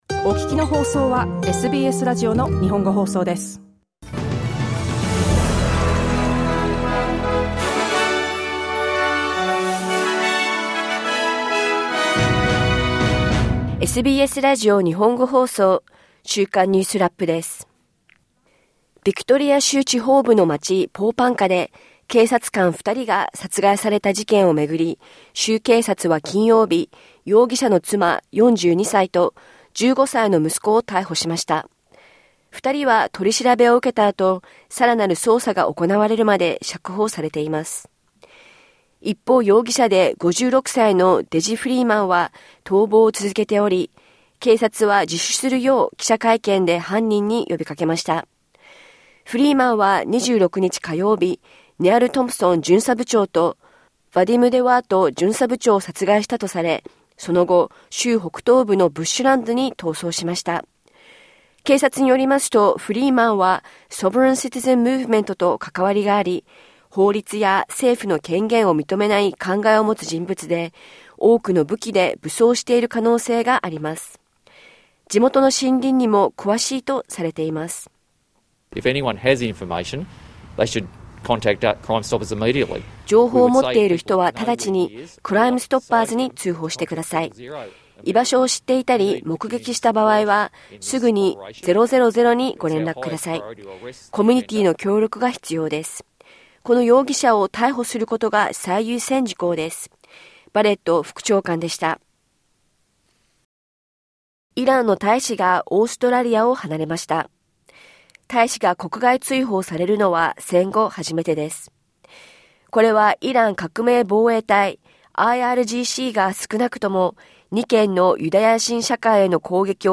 イスラエルの戦車がガザ市の新たな地域に進入、住宅に砲撃を続けており、住民は避難を余儀なくされています。1週間を振り返るニュースラップです。